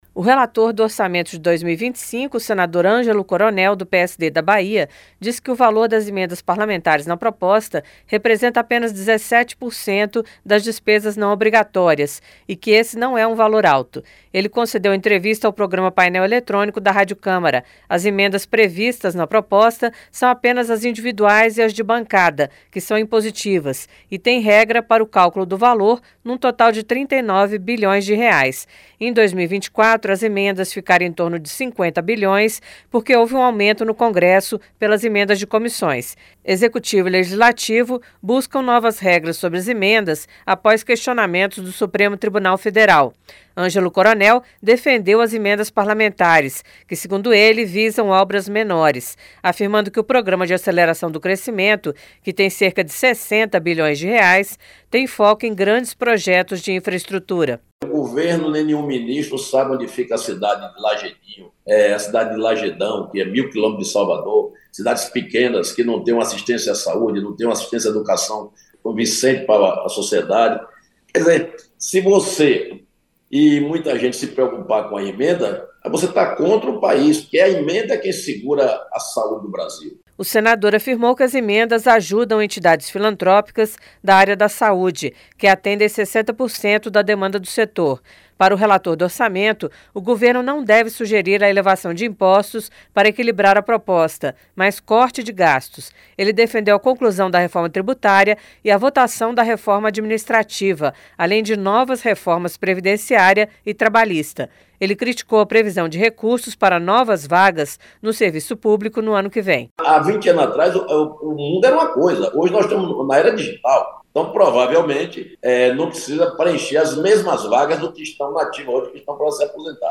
O relator do Orçamento de 2025 (PLN 26/24), senador Angelo Coronel (PSD-BA), disse que o valor das emendas parlamentares na proposta representa apenas 17% das despesas não obrigatórias e que esse não é um valor alto. Ele concedeu entrevista ao programa Painel Eletrônico, da Rádio Câmara.